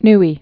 (nē)